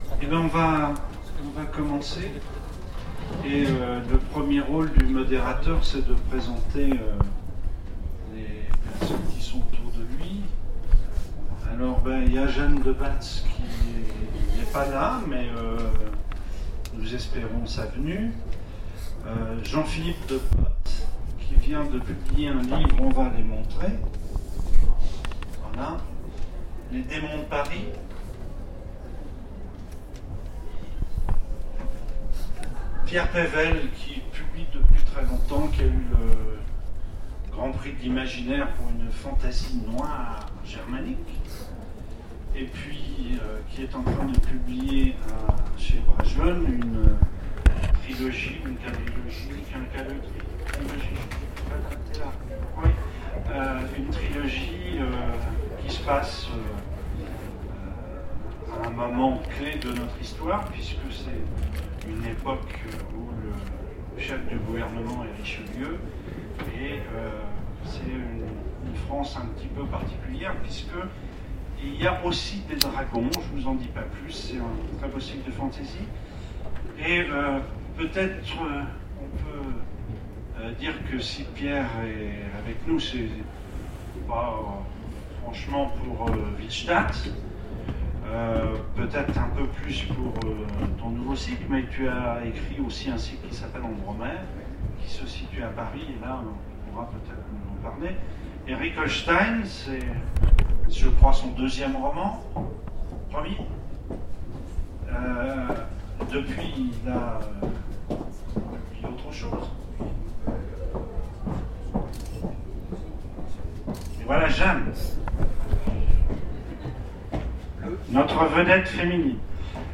Voici l'enregistrement de la conférence Paris… Ah, Paris ! Ville rêvée, ville de rêves… aux Imaginales